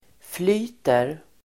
Uttal: [fl'y:ter]